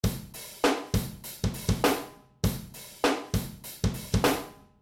基本韵律鼓
描述：原始的鼓声，没有添加任何效果。真正简单的节拍，100bpm。
Tag: 100 bpm Rock Loops Drum Loops 826.92 KB wav Key : Unknown